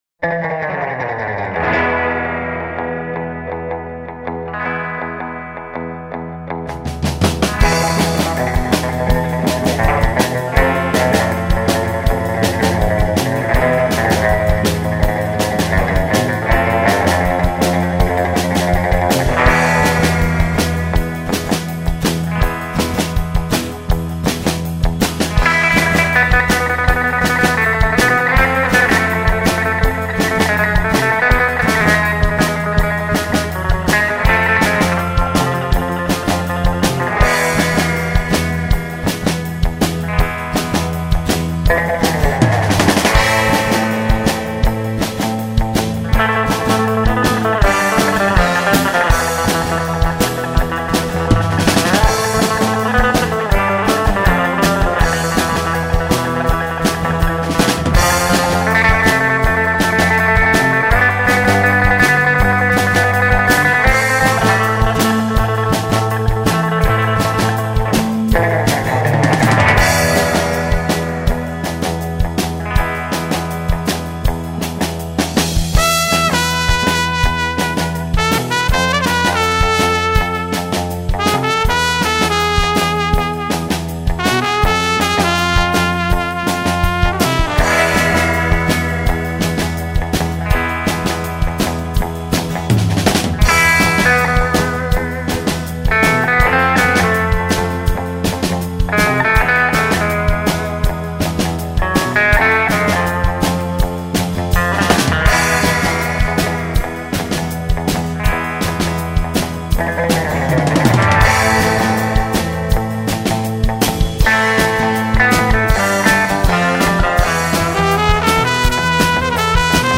ROCK AND COUNTRY SURF